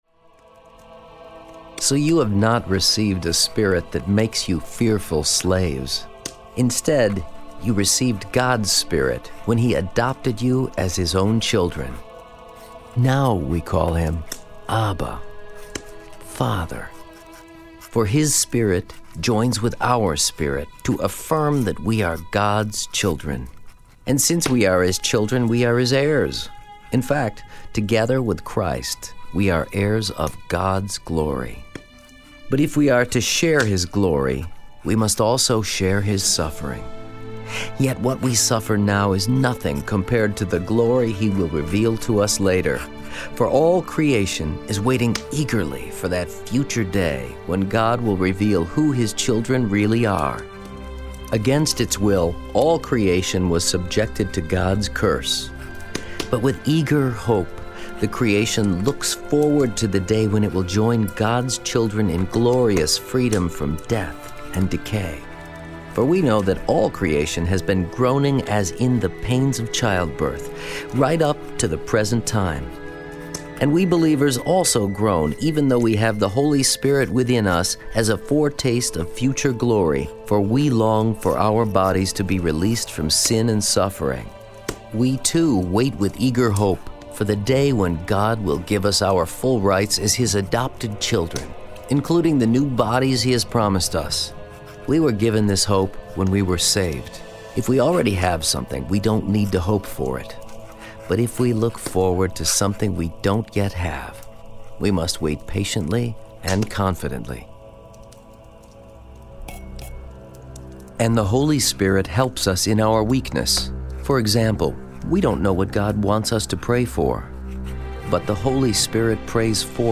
And the Breathe Audio New Testament, is a remarkably vivid listening experience that will transport you into the world of the Bible. A cast of over 100 actors, an original score, and stunningly realistic sound effects combine to create a true “you are there” experience for the listener.